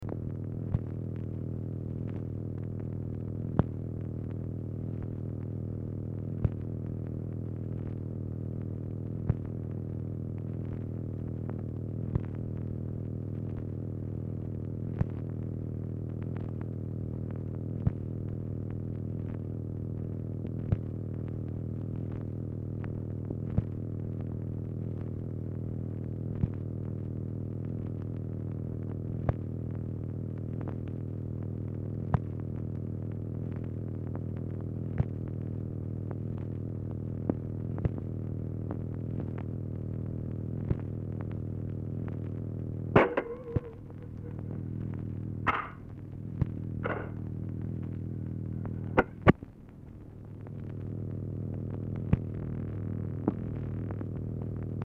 Telephone conversation # 96, sound recording, MACHINE NOISE, 11/27/1963, time unknown | Discover LBJ
Format Dictation belt
Specific Item Type Telephone conversation